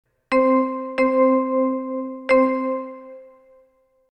ritme_5
ritme_5.mp3